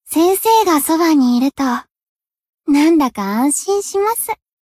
贡献 ） 分类:蔚蓝档案 分类:蔚蓝档案语音 协议:Copyright 您不可以覆盖此文件。